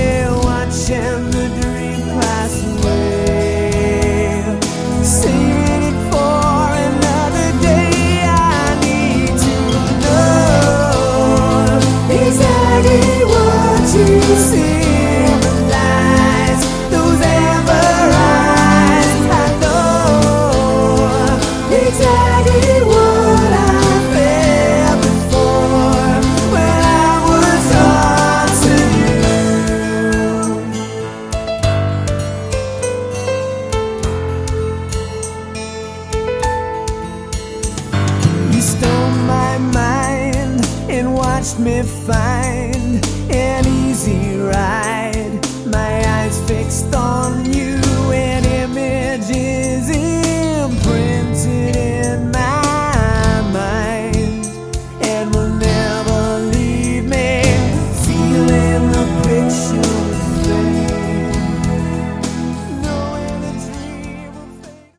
Category: AOR
vocals, guitars, keys
bass, vocals
drums, vocals